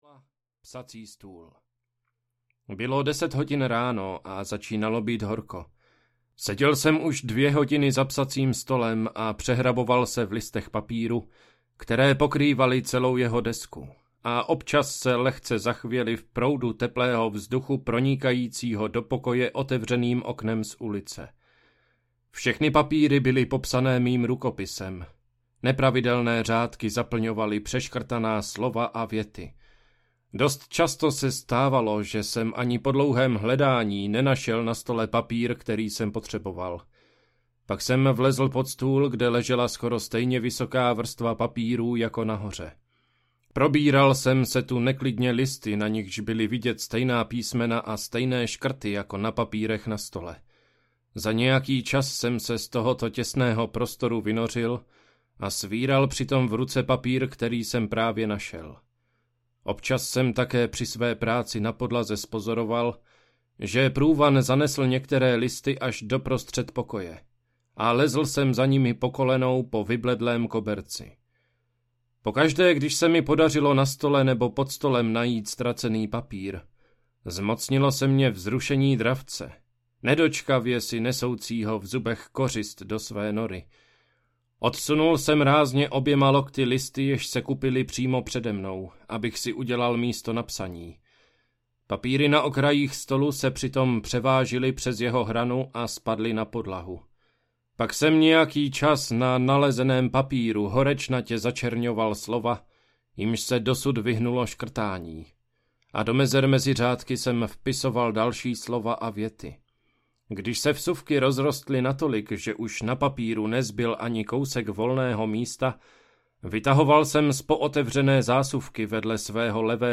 Prázdné ulice audiokniha
Ukázka z knihy